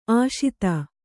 ♪ āśita